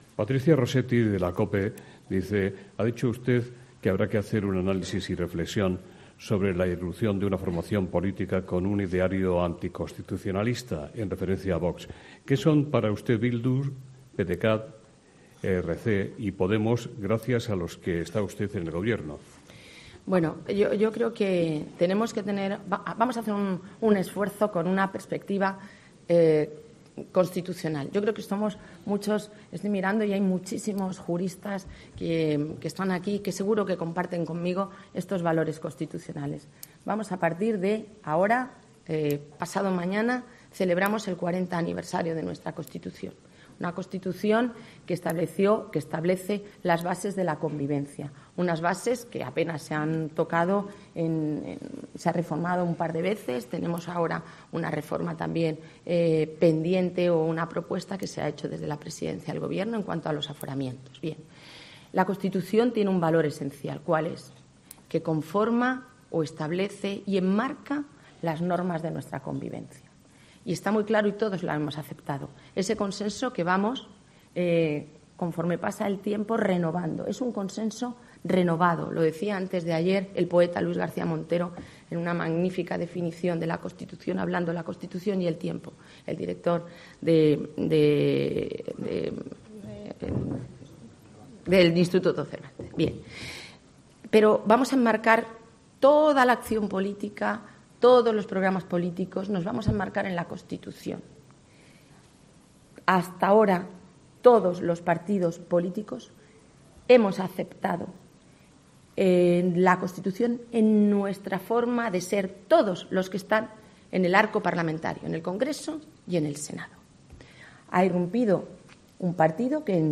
La ministra de Justicia, Dolores Delgado, ha establecido este martes diferencias entre los partidos que apoyaron la moción de censura y que, a su juicio, aceptan la Constitución y son "plenamente constitucionales", y Vox, que tiene en su ideario "elementos anticonstitucionales". La ministra, en un desayuno informativo de Nueva Economía Fórum, ha hecho este análisis de lo ocurrido en las elecciones andaluzas, una cita en la que considera que no hubo ninguna "circunstancia anómala" más allá de la irrupción de Vox con doce diputados.